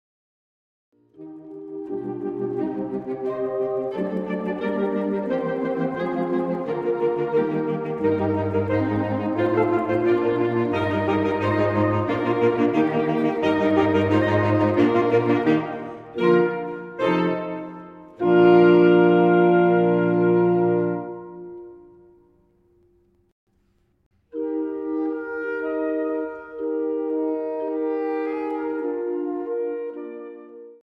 Allegro 0:22